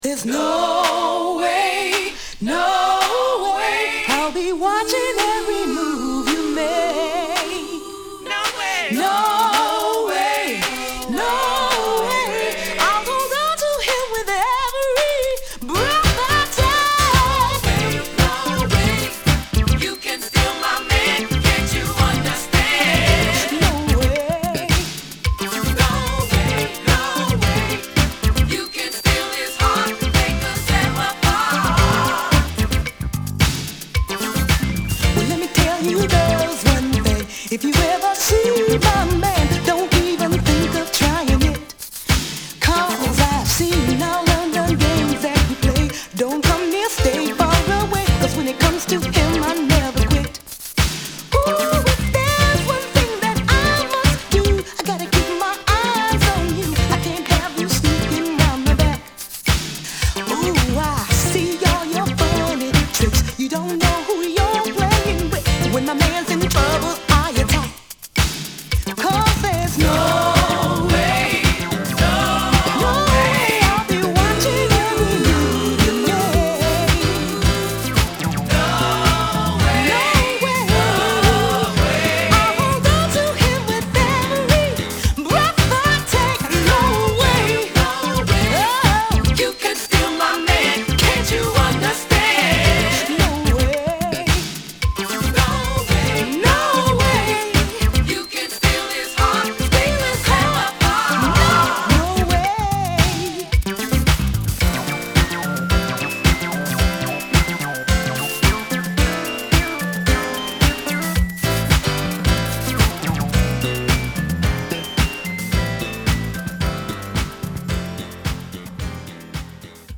NY Disco Group
Good 80's Modern Disco!!